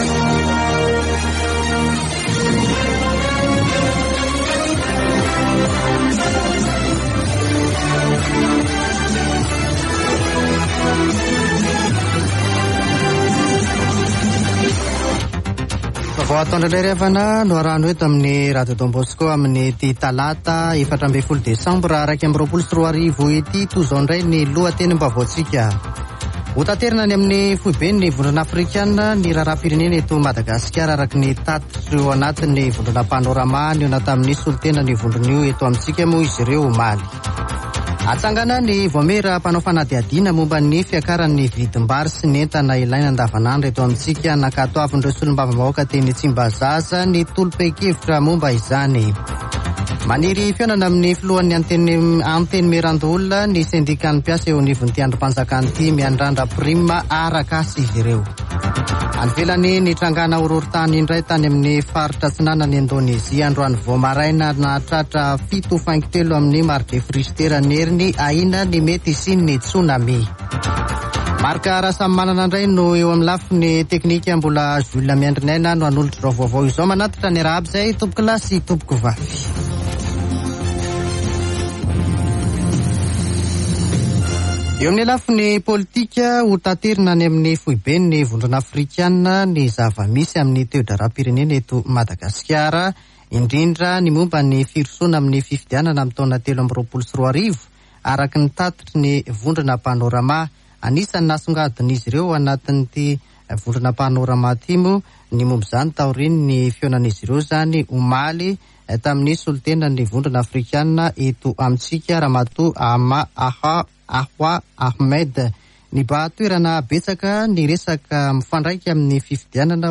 [Vaovao antoandro] Talata 14 desambra 2021